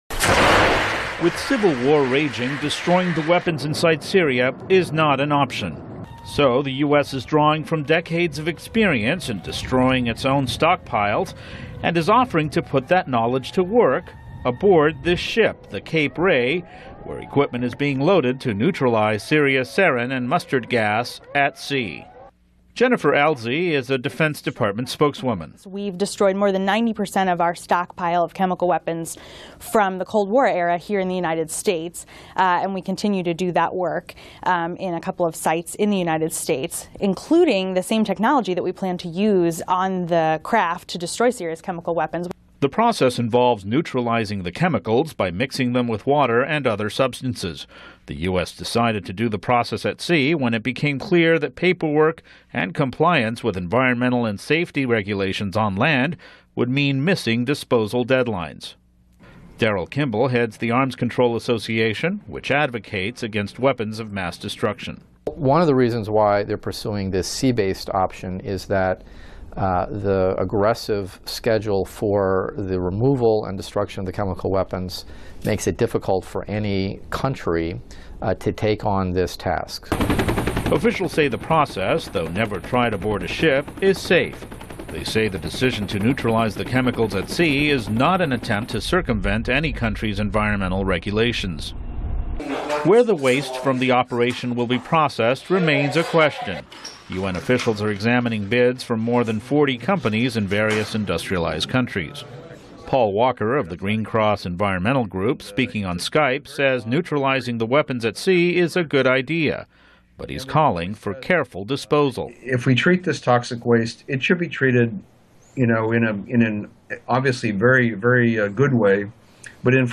您现在的位置：VOA > VOA常速英语 > 12月份目录 > 美国准备销毁叙利亚化学武器